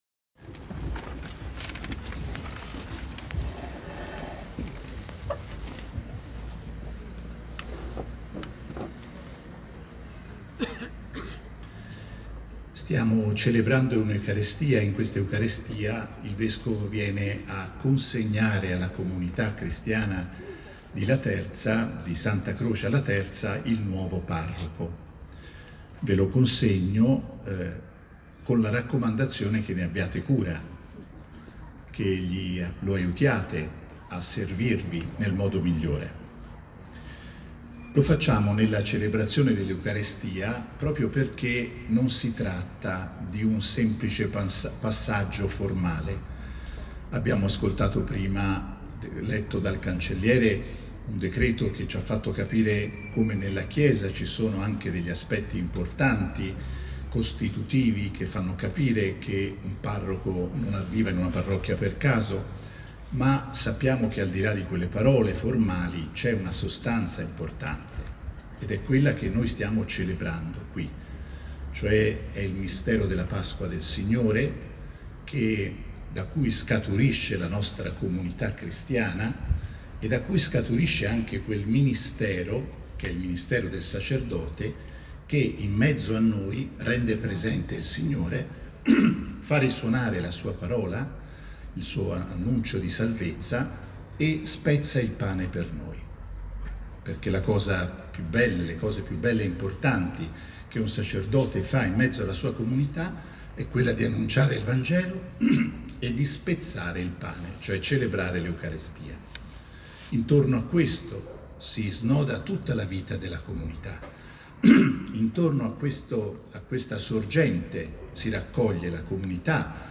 Omelia del Vescovo nella Concelebrazione Eucaristica per l'ingresso di